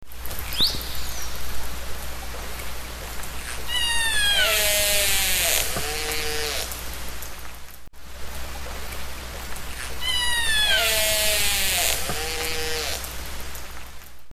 Orca:
Orca.mp3